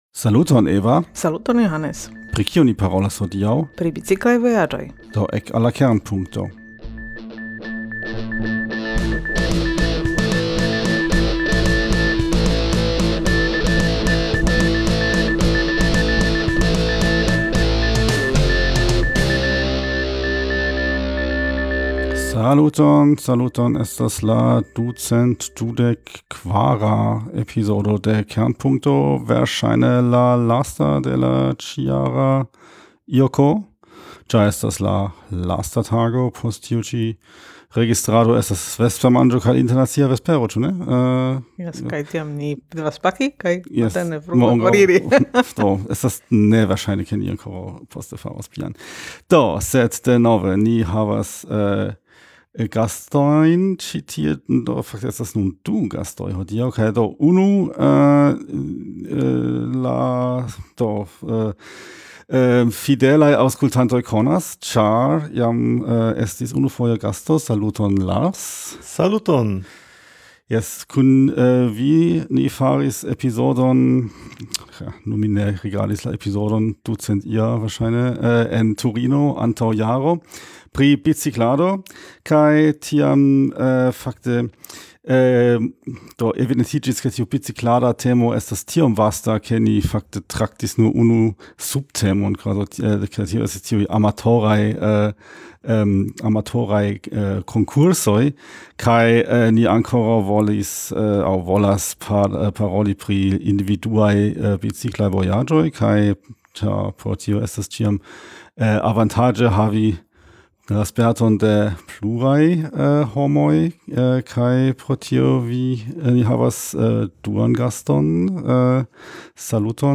Ankaŭ al la ĉi-jara IJK alvenis grupo de biciklantoj de eksterlande. Ni parolas kun du el ili pri iliaj longjaraj spertoj kiel organizi kaj travivi longdistancajn biciklajn vojaĝojn.